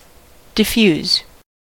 diffuse-verb: Wikimedia Commons US English Pronunciations
En-us-diffuse-verb.WAV